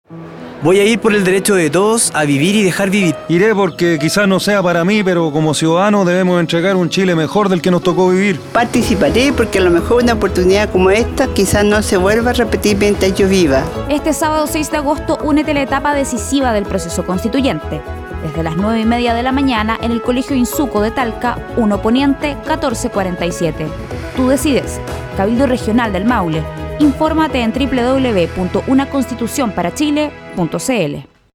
Audio promoción testimonial con detallada información de hora y lugar de realización de los cabildos regionales, Región del Maule 3.